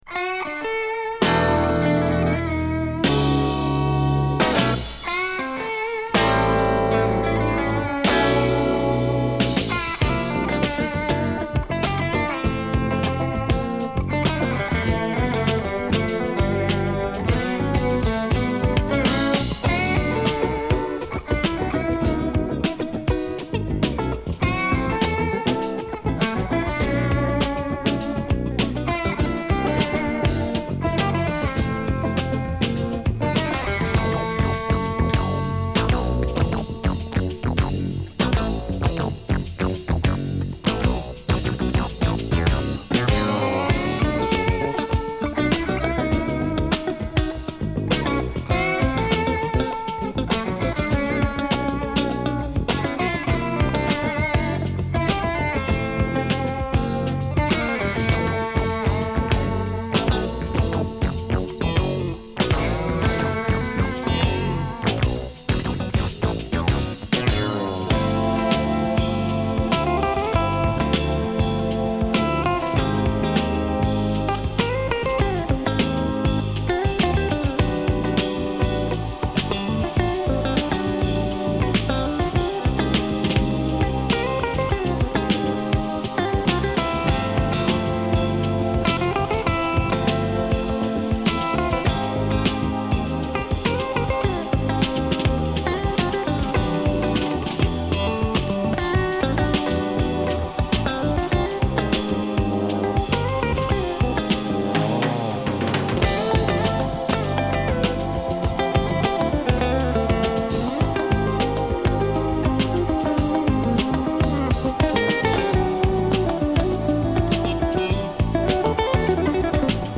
Sådär, nu har jag modifierat inspelningsförfarandet en bit: